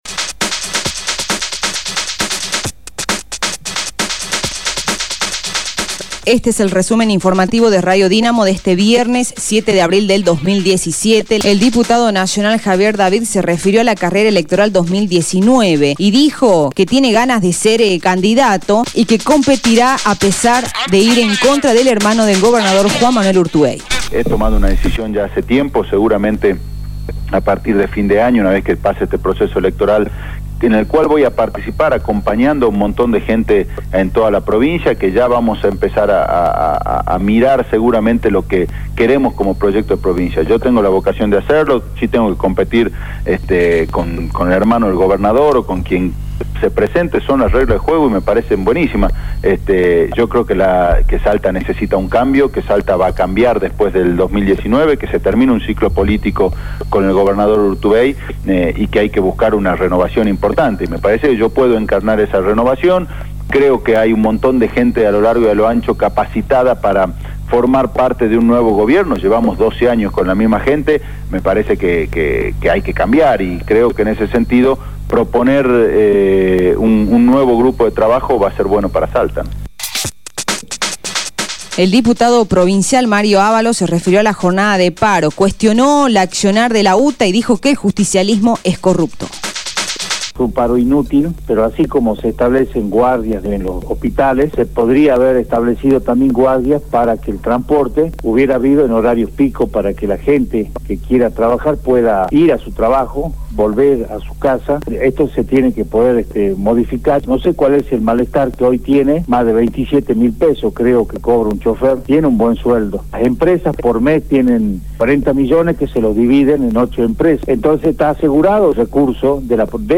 Resumen Informativo de Radio Dinamo del día 07/04/2017 2° Edición